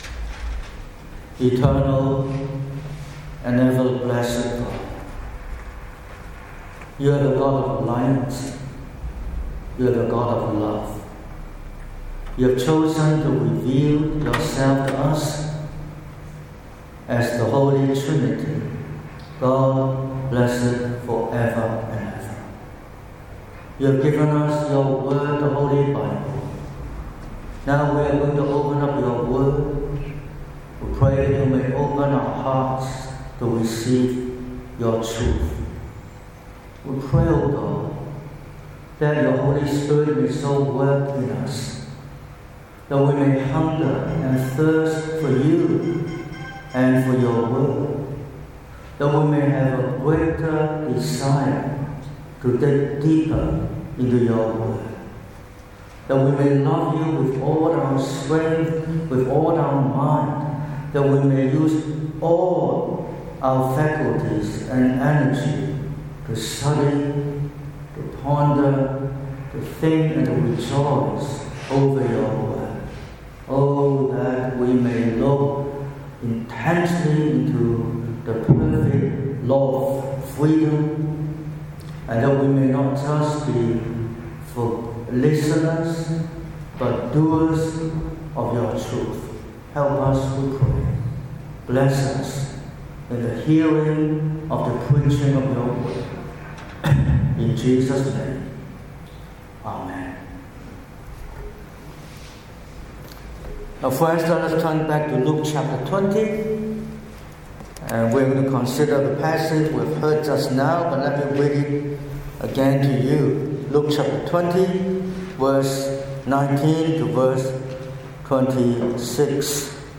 15/03/2026 – Morning Service: To pay or not to pay?
Sermon Outline: Luke 20:19–26 (NKJV) 19 And the chief priests and the scribes that very hour sought to lay hands on Him, but they feared the people—for they knew He had spoken this parable against them.